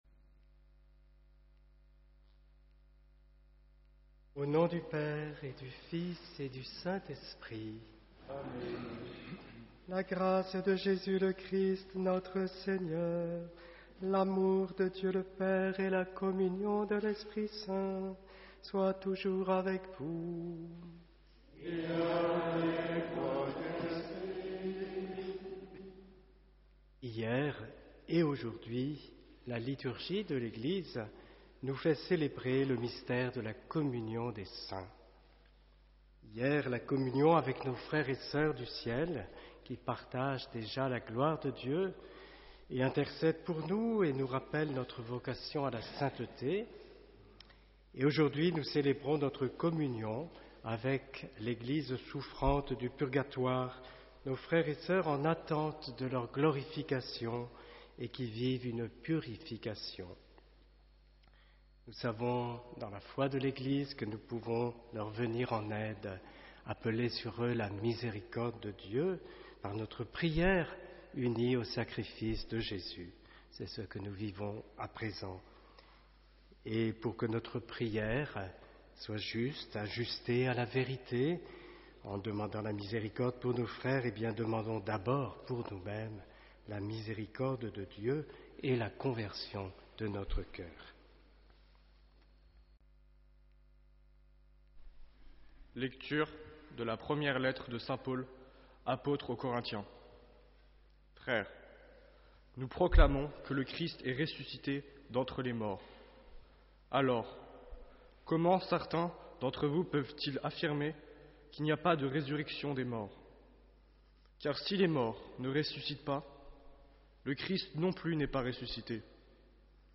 HOMÉLIE Le jour des morts n’existe pas, ce qui existe c’est la Communion des Saints, et elle nourrit notre espérance.